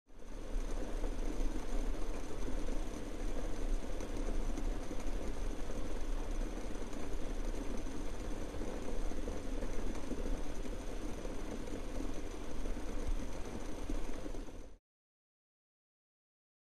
Boiling | Sneak On The Lot